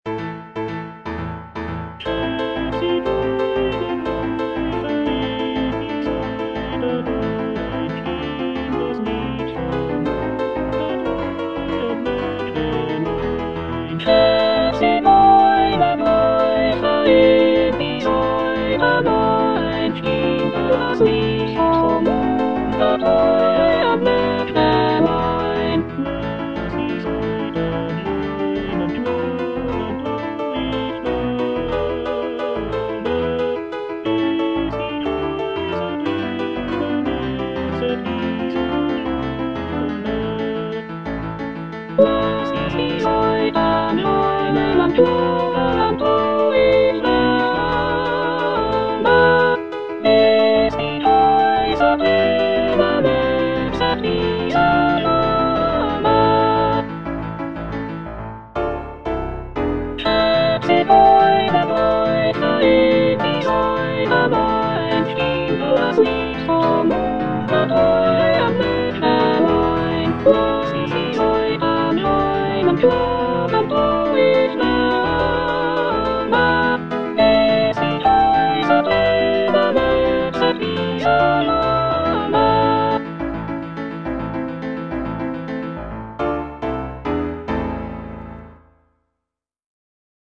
J. BRAHMS - HE, ZIGEUNER, GREIFE IN DIE SAITEN OP.103 NO.1 Soprano (Emphasised voice and other voices) Ads stop: auto-stop
The piece is written in a lively and energetic manner, incorporating elements of Hungarian folk music.